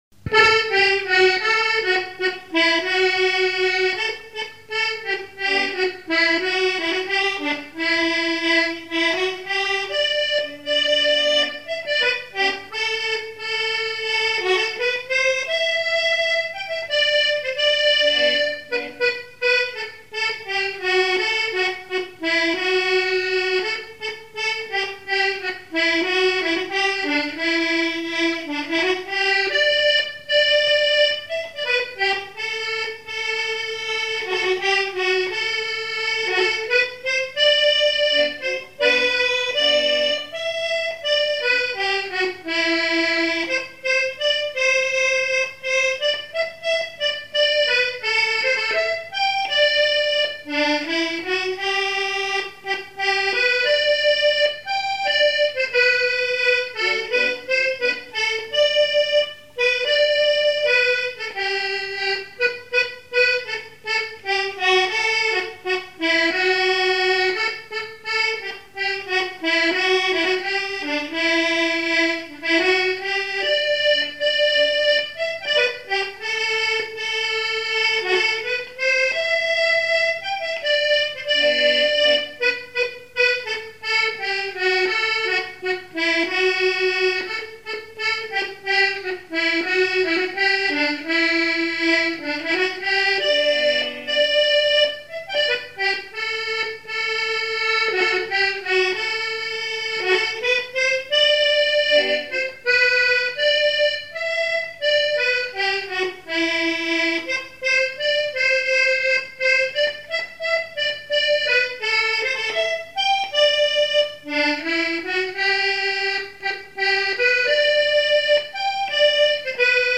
Résumé instrumental
danse : java
Répertoire de musique traditionnelle
Pièce musicale inédite